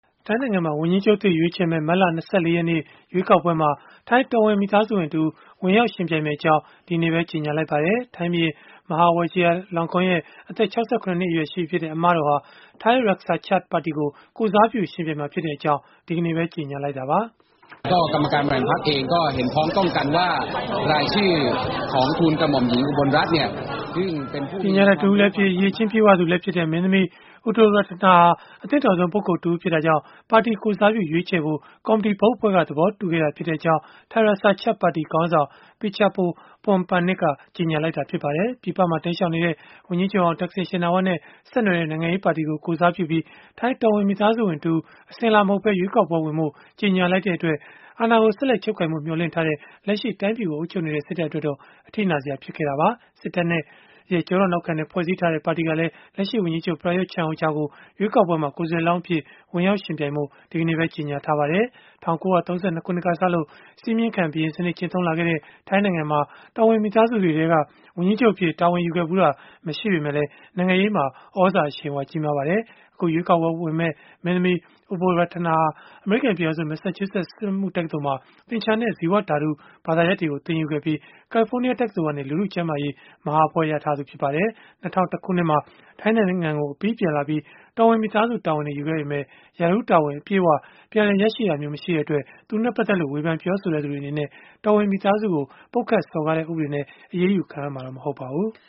ထိုင်းဘုရင့်အမတော် ဝန်ကြီးချုပ်လောင်းအဖြစ် ထိုင်းရွေးကောက်ပွဲဝင်မည် (News)